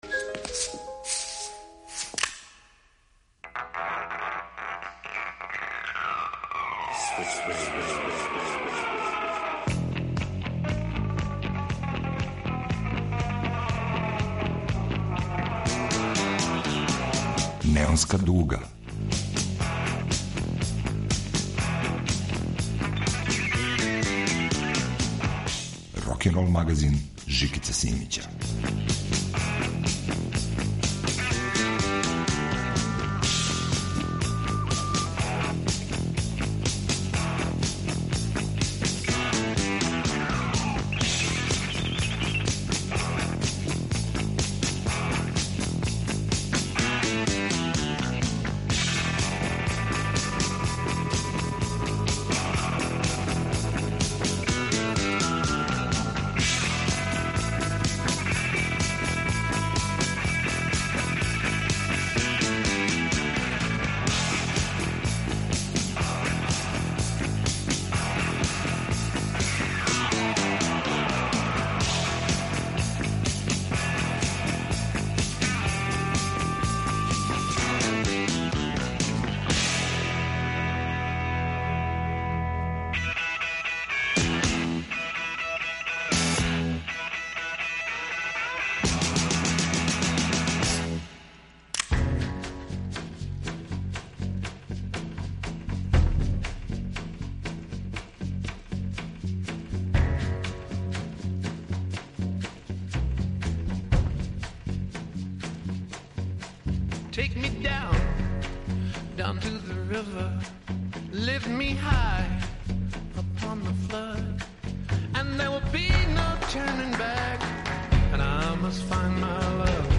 Рокенрол као музички скор за живот на дивљој страни.
Старо и ново, традиционално и модерно смењују се у фуриозном ритму рокенрола.